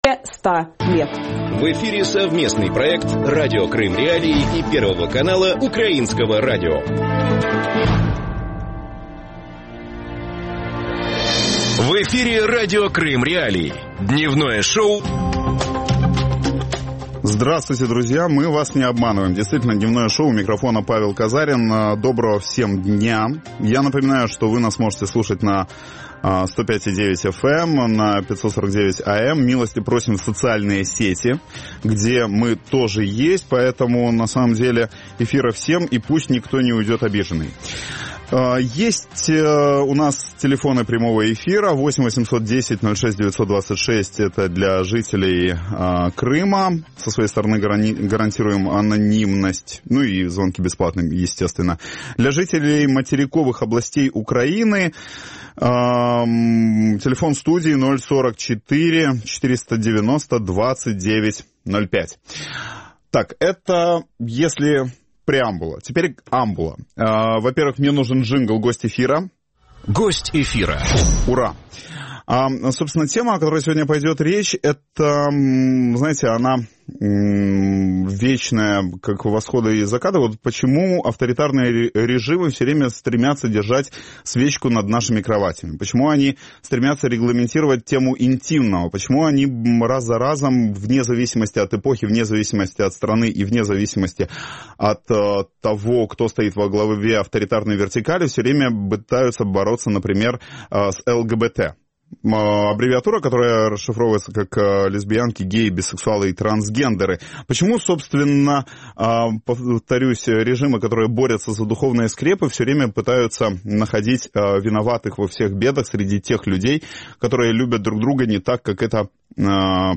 FAQ о геях, лесбиянках, бисексуалах и трансгендерах. Об этом – в проекте «Дневное шоу» в эфире Радио Крым.Реалии с 12:10 до 12:40.